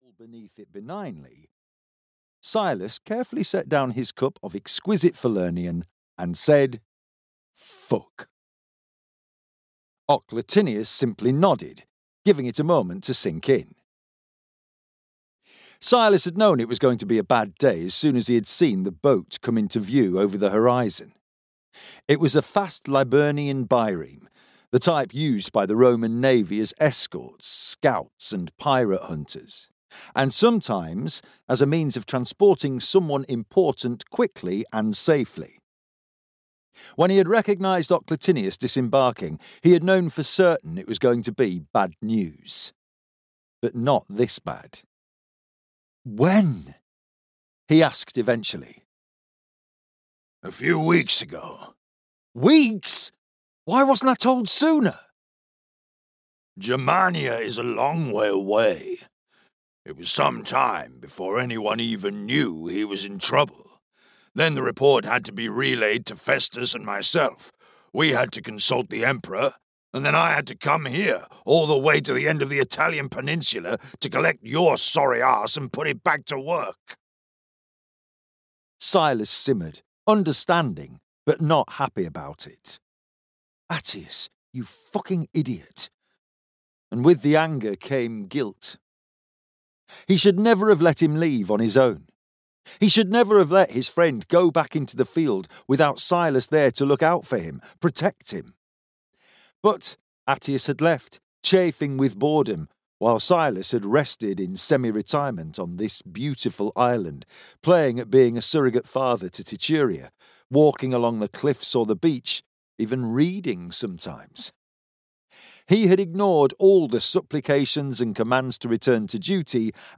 Emperor's Spear (EN) audiokniha
Ukázka z knihy